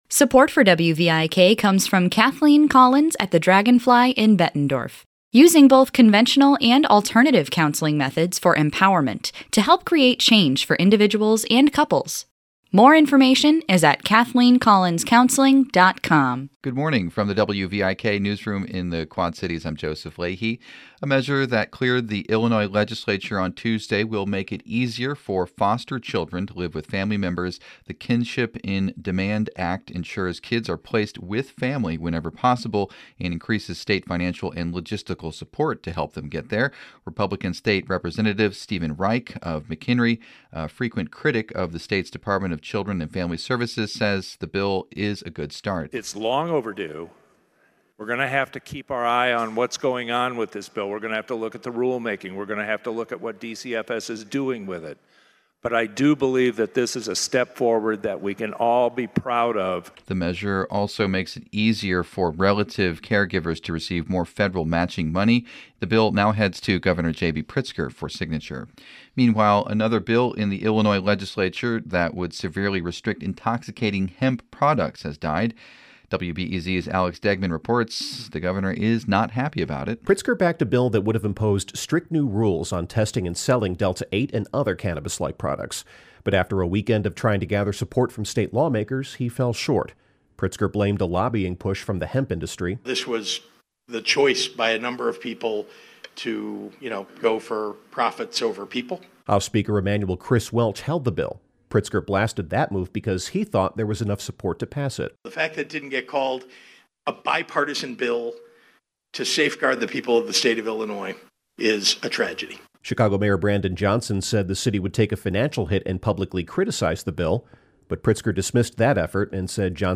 Morning headlines from WVIK News.